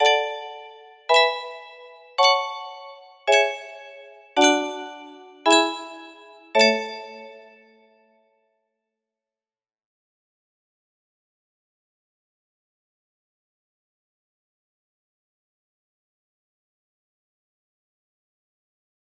Progression music box melody
Full range 60